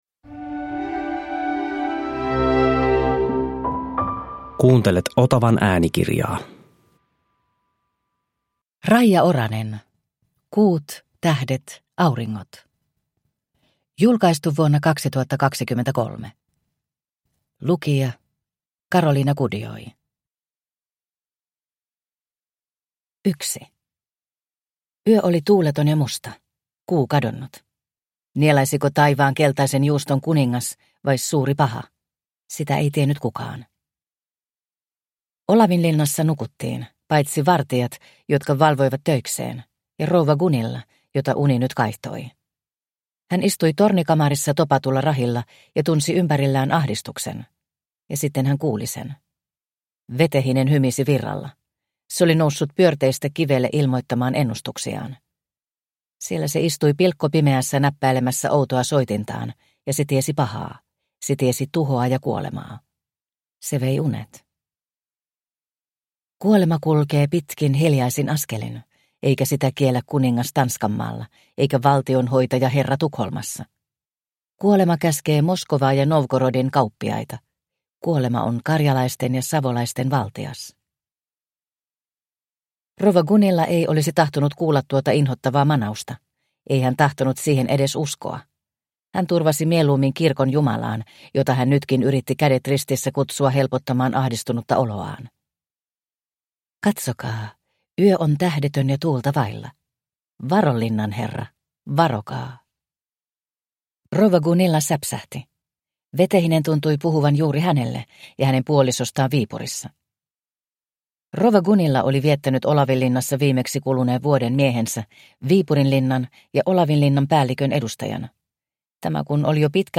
Kuut, tähdet, auringot – Ljudbok – Laddas ner